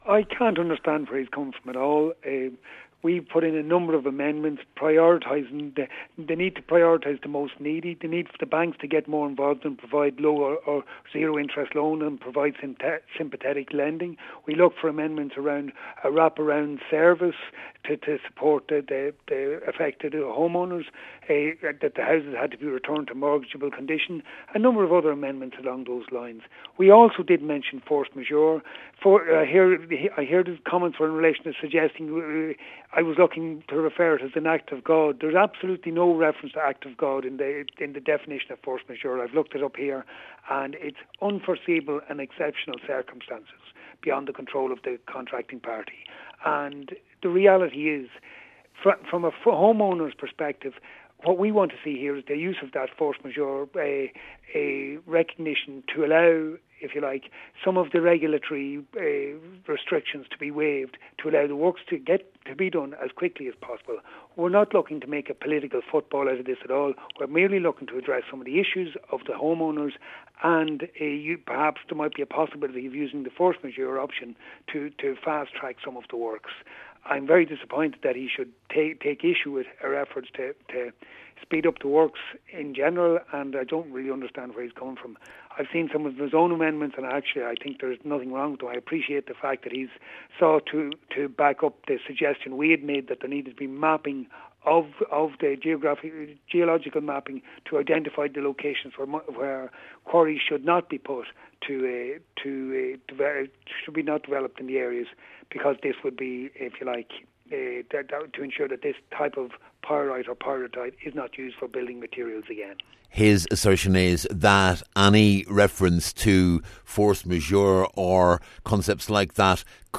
He says the Force Majeure reference is intended to clear the way for necessary work to take place speedily: